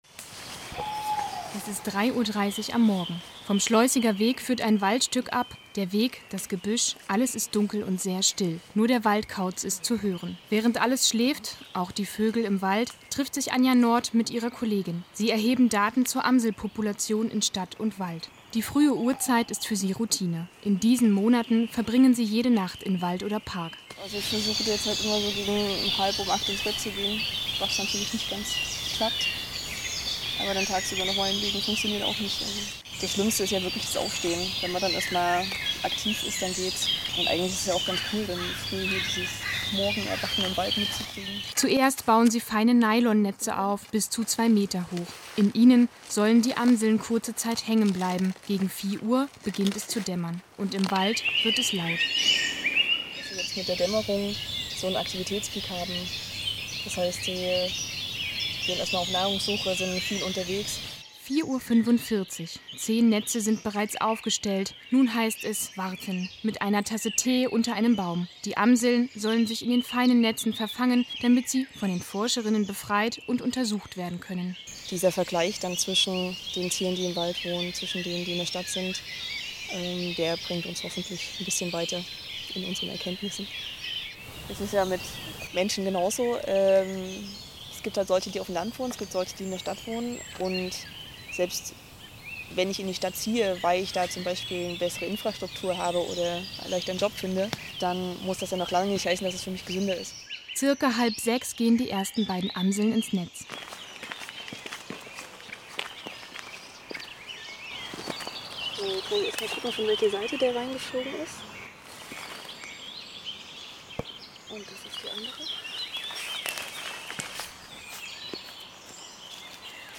SWR: Radiobeitrag zu Einfluss der Lichtverschmutzung auf Amseln